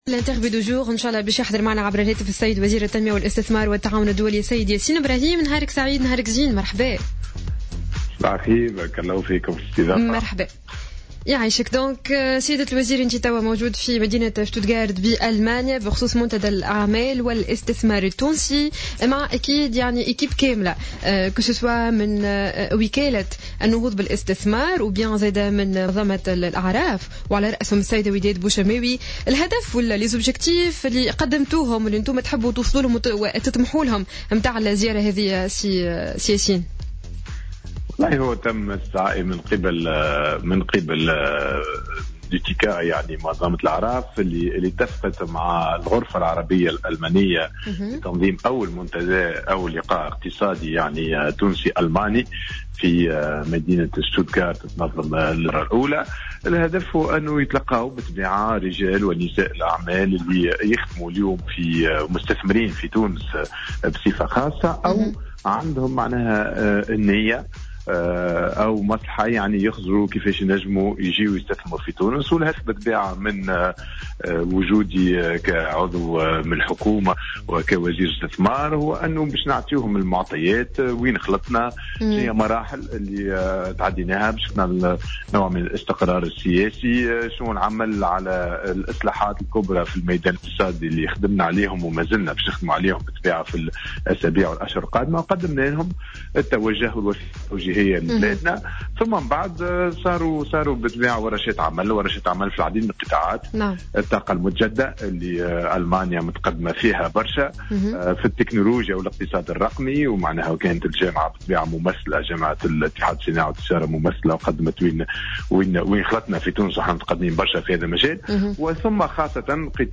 أكد وزير التنمية والاستثمار والتعاون الدولي ياسين براهيم في مداخلة له على الجوهرة "اف ام" صباح اليوم الثلاثاء 6 أكتوبر 2015 أن مشاركته في منتدى الأعمال والإستثمار التونسي الذي ينعقد حاليا وللمرة الأولى في ألمانيا جاء بدعوة من منظمة الأعراف مؤكدا أن الهدف من وجوده كعضو في الحكومة هو إعطاء المستثمرين الألمان المعطيات اللازمة بخصوص ماوصلت إليه تونس على صعيد الإصلاحات الإقتصادية الكبرى التي بدأت فيها وفق قوله.